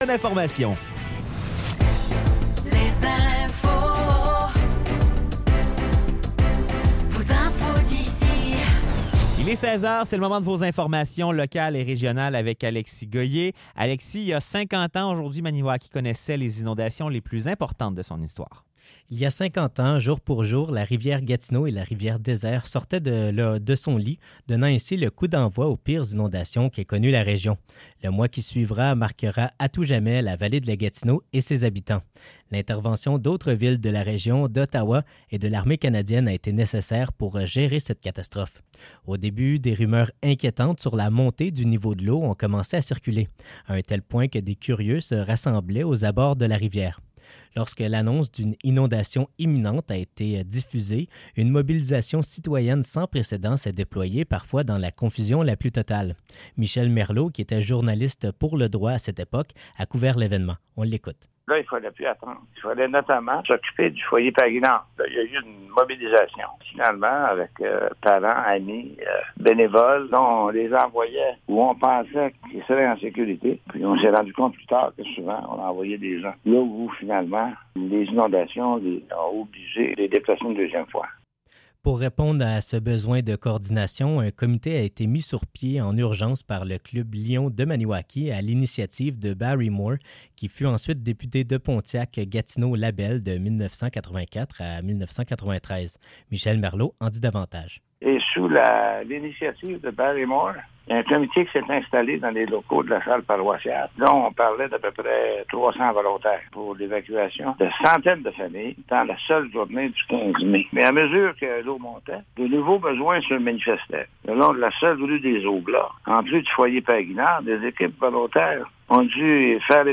Nouvelles locales - 14 mai 2024 - 16 h